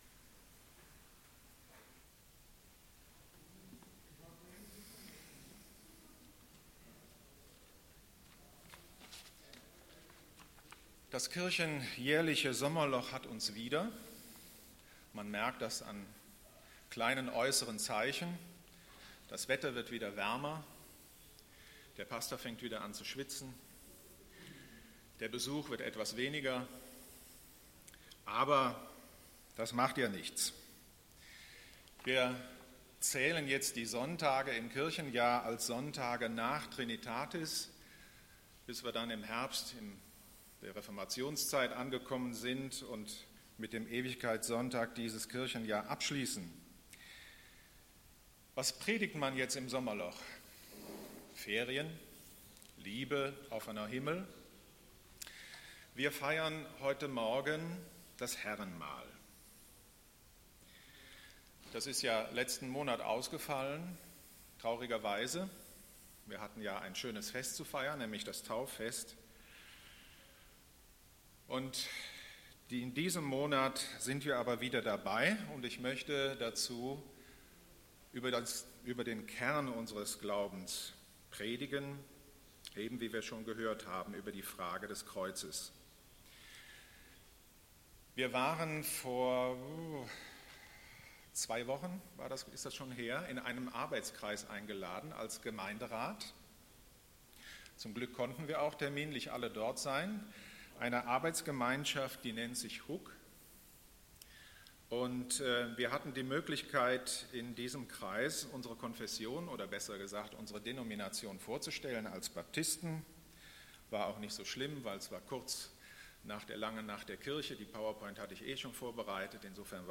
Predigt vom 26.06.2016